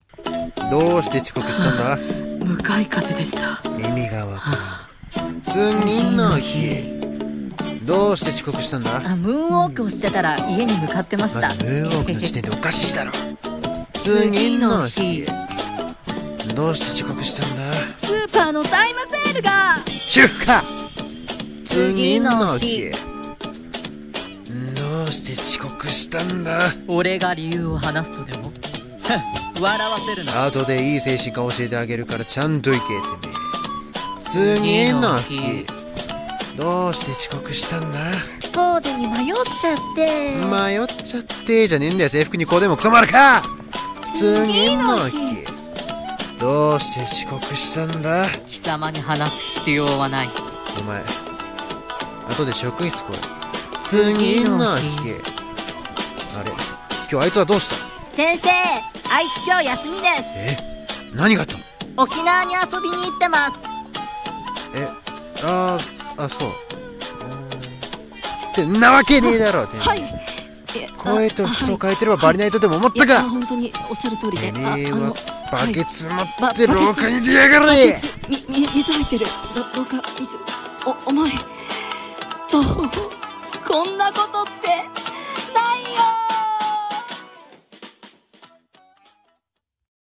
声劇「遅刻の理由」